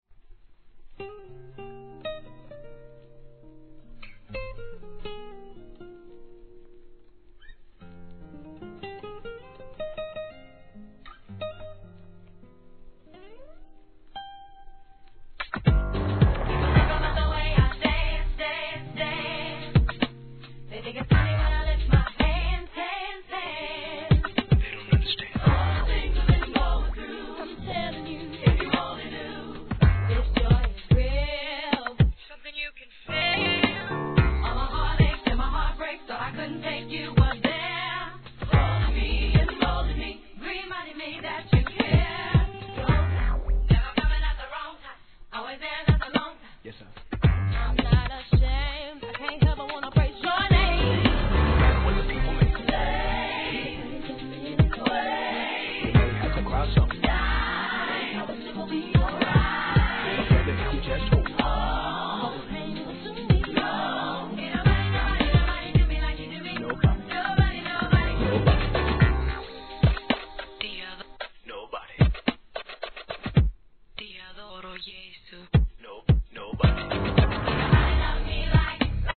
HIP HOP/R&B
哀愁のアコースティックが印象的な2000年インディーR&B!!